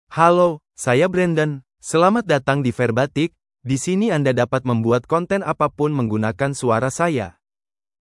MaleIndonesian (Indonesia)
BrandonMale Indonesian AI voice
Brandon is a male AI voice for Indonesian (Indonesia).
Voice sample
Male